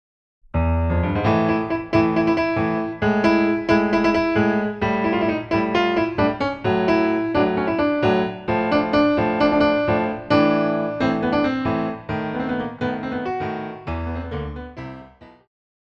古典,流行
小提琴
钢琴
演奏曲
世界音乐
仅伴奏
没有主奏
没有节拍器